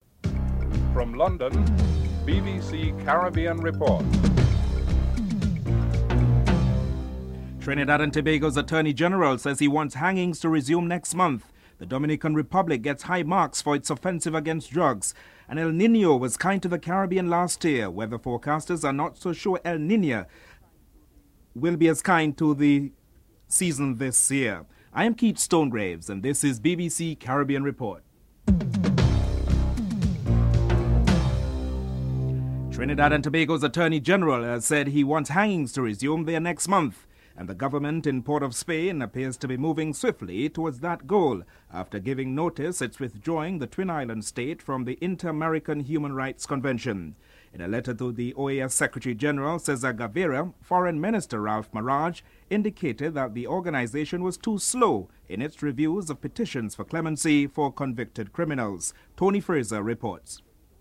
1. Headlines (00:00-00:31)
Interview with Chairman Lester Bird and Chief Minister of Montserrat David Brandt (02:10-04:46)